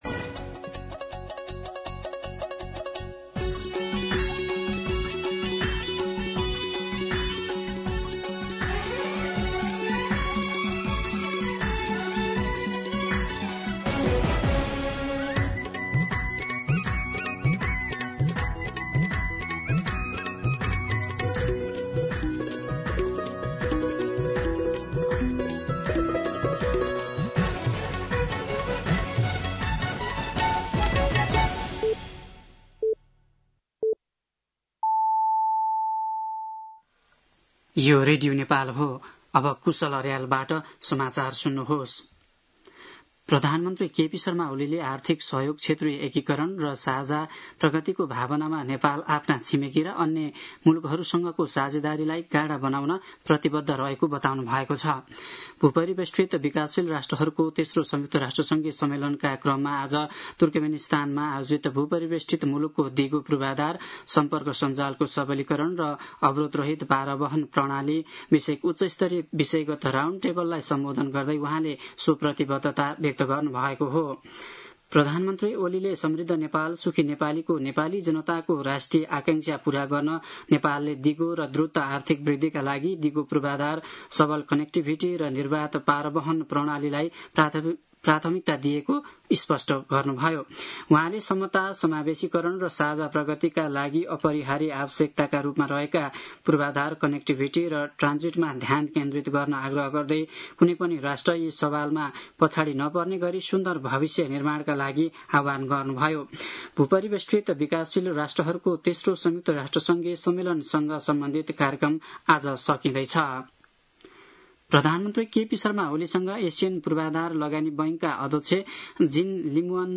दिउँसो ४ बजेको नेपाली समाचार : २२ साउन , २०८२
4-pm-Nepali-News-2.mp3